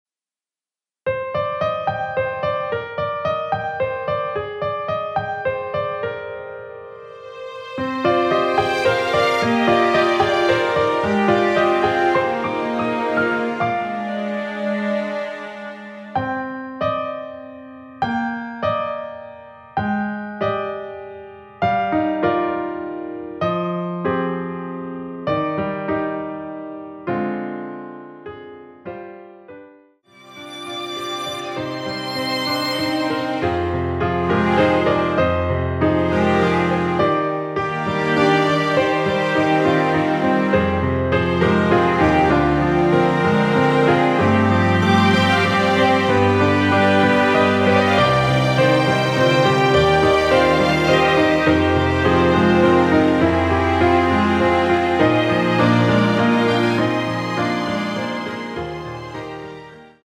Cm
앞부분30초, 뒷부분30초씩 편집해서 올려 드리고 있습니다.
중간에 음이 끈어지고 다시 나오는 이유는
위처럼 미리듣기를 만들어서 그렇습니다.